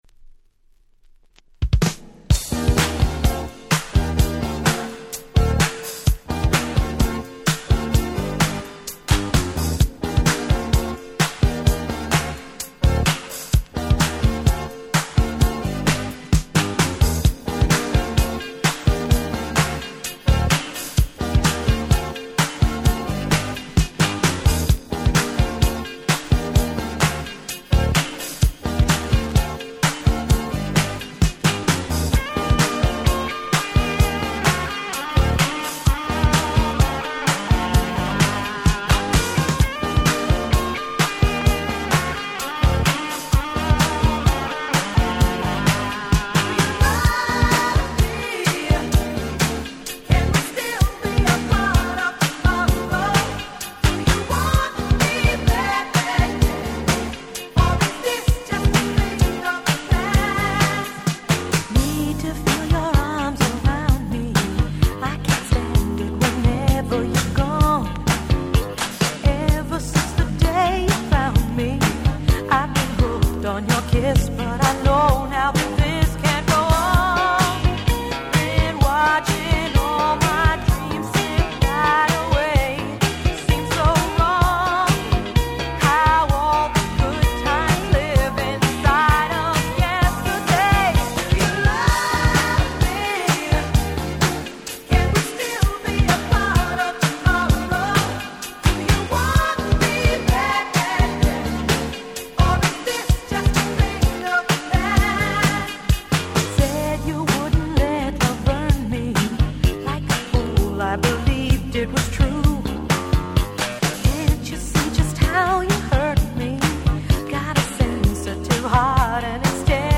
81' Super Hit Disco/Dance Classic !!